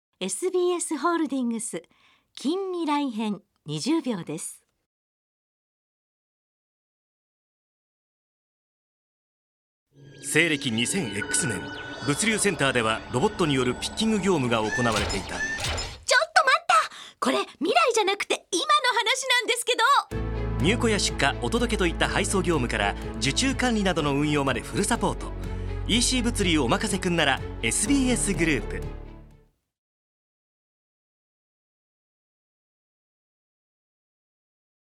現在「ニッポン放送ショウアップナイター」で放送中のラジオCMです。
SBSグループ ラジオCM「近未来」篇 20秒 [mp3]